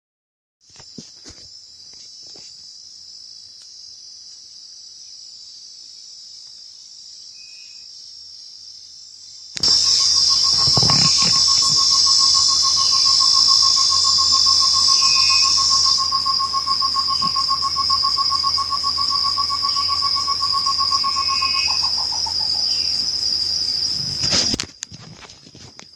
Tovaca-campainha (Chamaeza campanisona)
Nome em Inglês: Short-tailed Antthrush
Condição: Selvagem
Certeza: Gravado Vocal